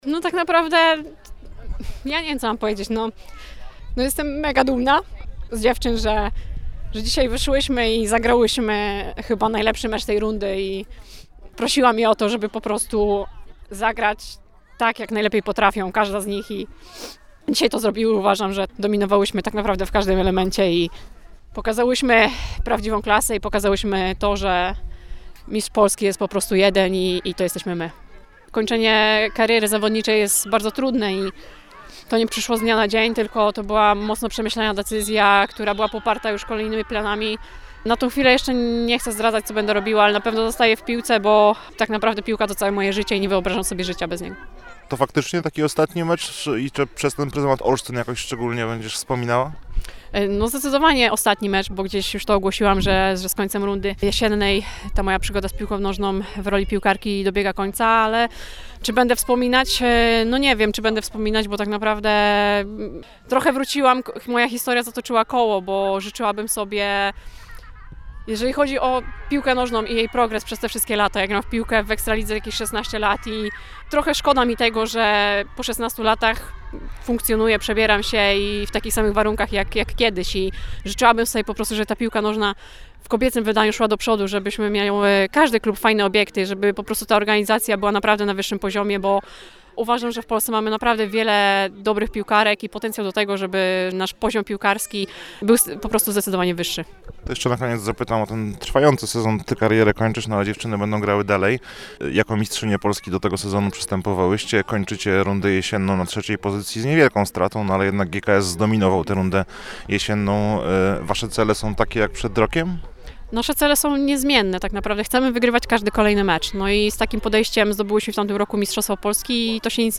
– mówiła wyraźnie wzruszona